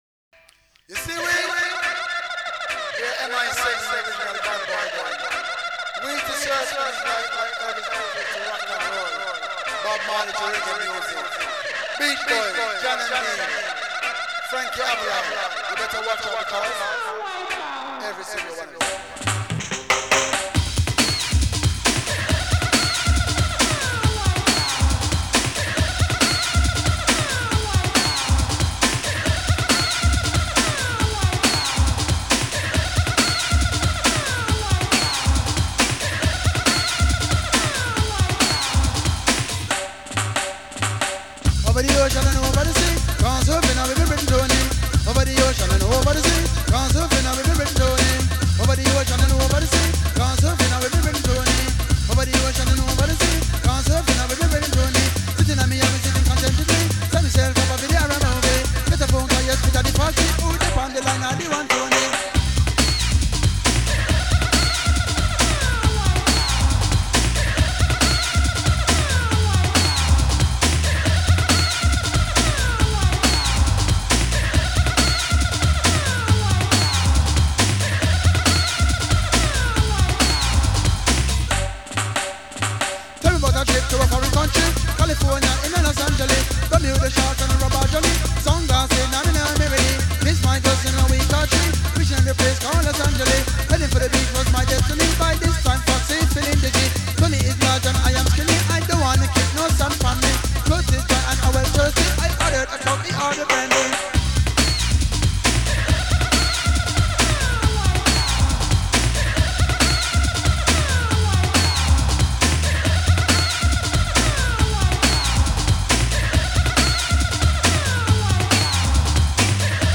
la version ragga chantée